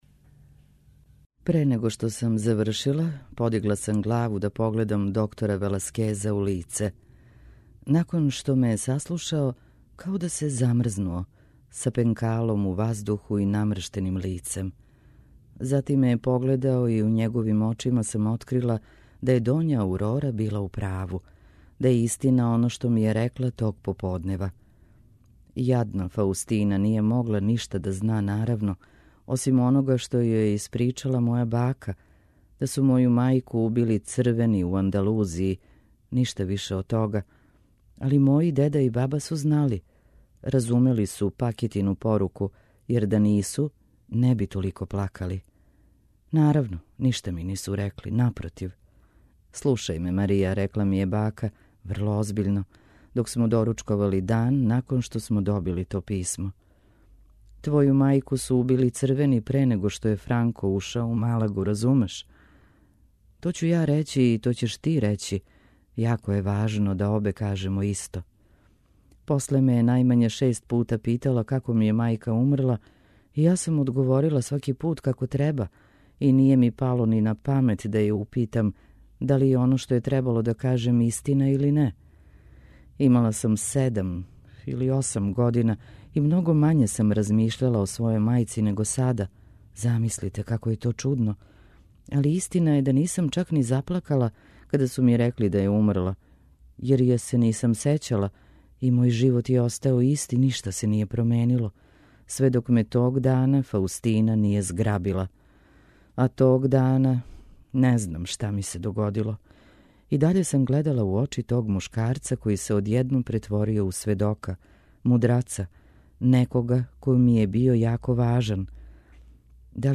У емисији Путеви прозе, можете слушати делове романа савремене шпанске списатељице Алмудене Грандес „Франкенштајнова мајка”.
Књига за слушање